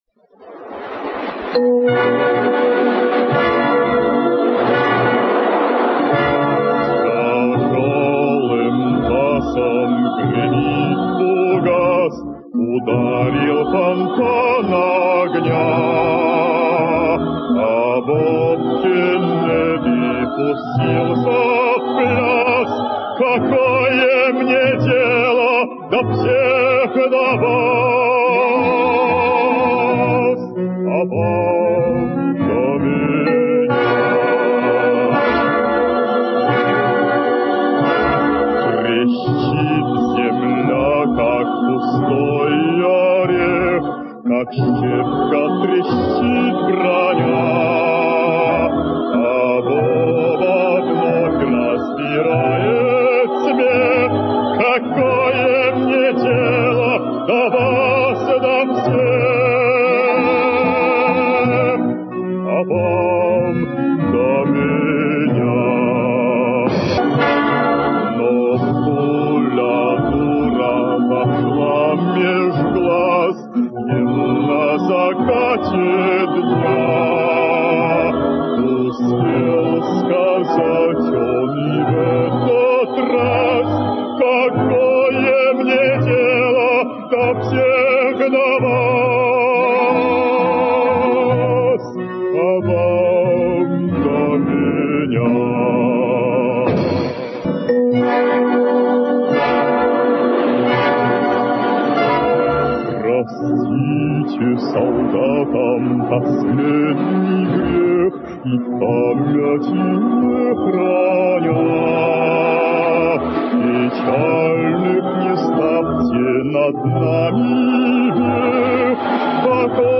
обладавший редким певческим голосом — бассо-профундо.